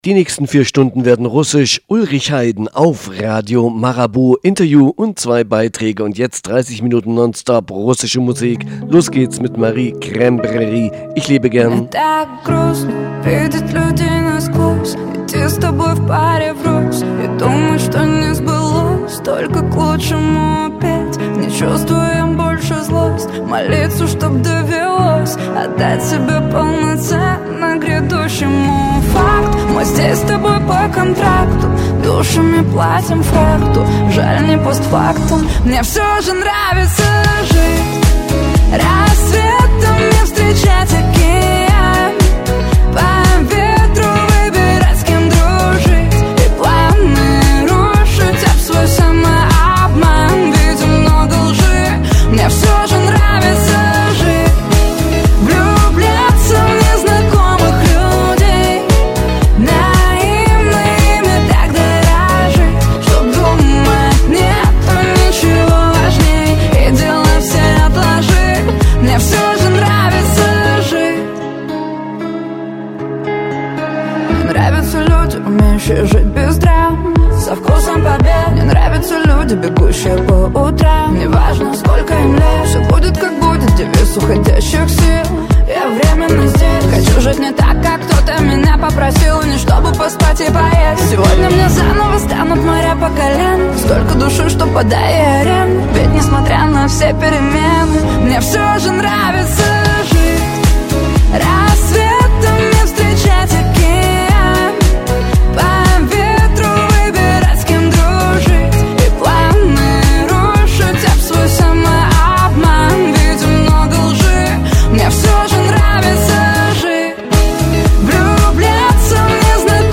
Mit guter, auch eigener Musik des Gastes.